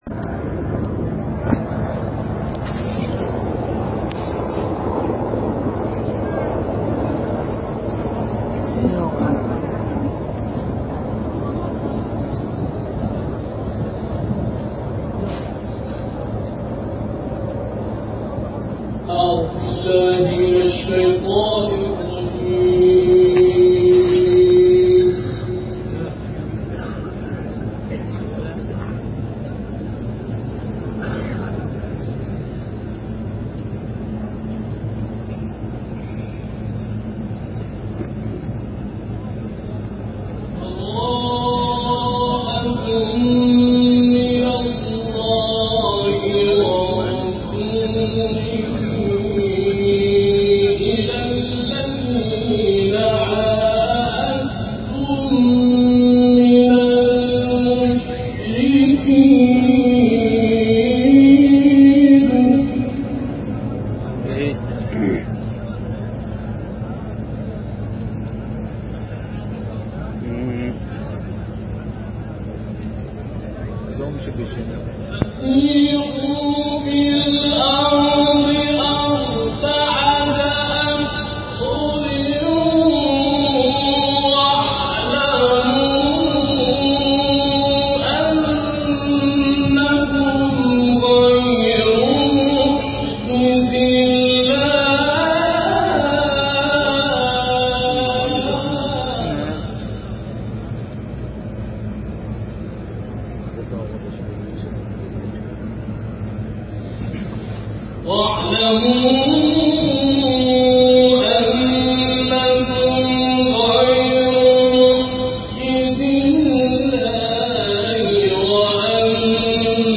تلاوت
در مراسم برائت از مشرکان